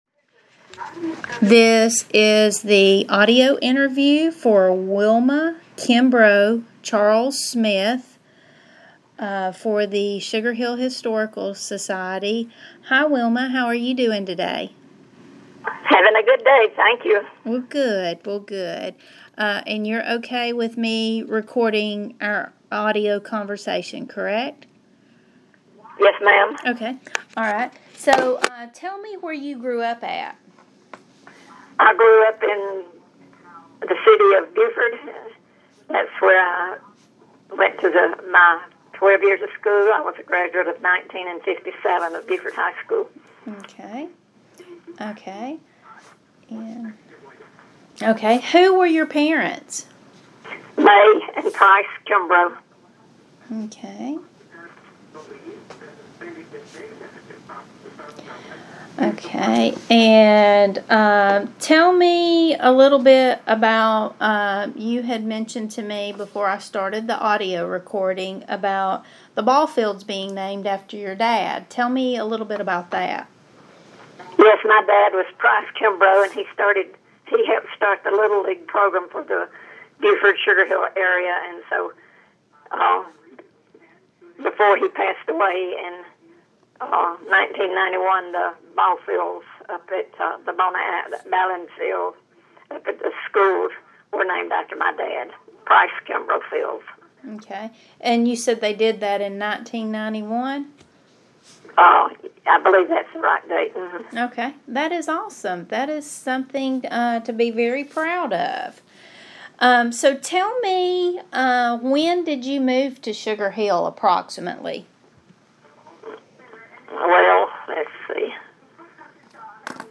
Audio Interivew Subject Oral histories Sugar Hill
via telephone